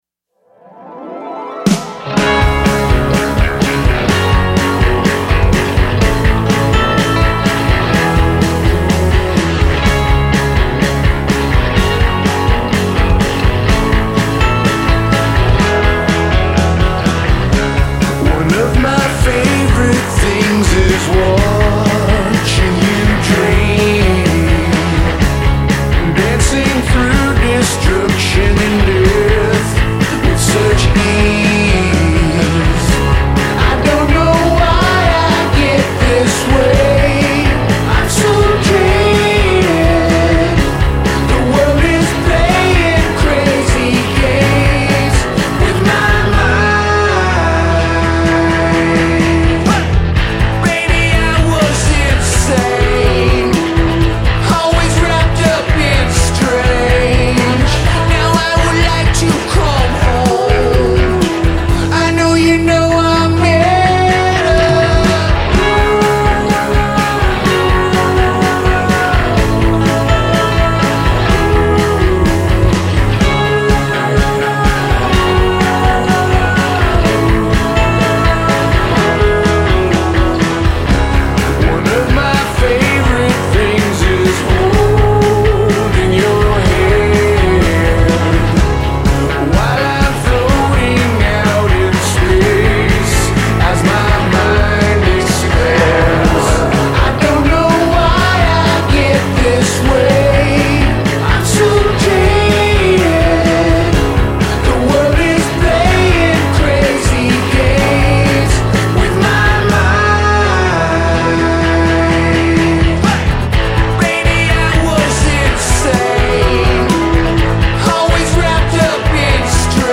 stoner-pop, bubble-grunge, space rockers
classic psych-rock power pop